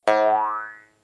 Quan volem posar so a la placa PyBadge necessitem fitxers de tipus WAV mono de 16 bits i a una freqüència de 22050 Hz.
boing.wav